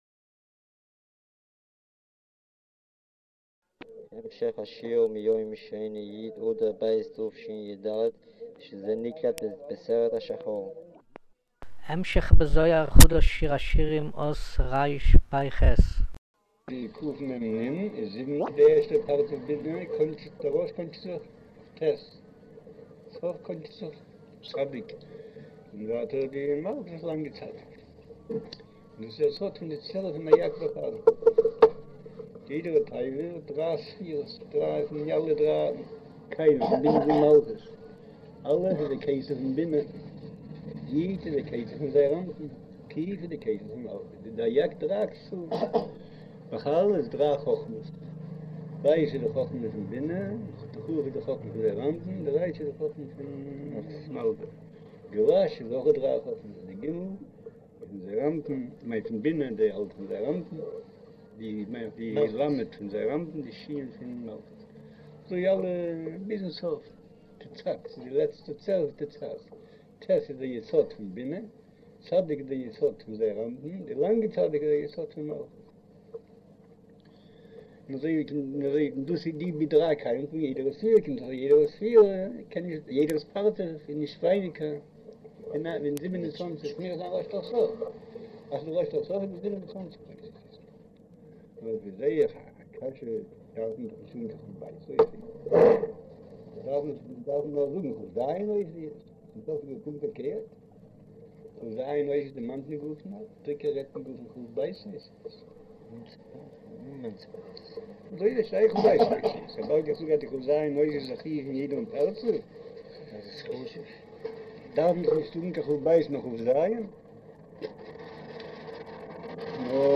אודיו - שיעור מבעל הסולם זהר חדש, שיר השירים, אות רפח' - רצג'